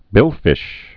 (bĭlfĭsh)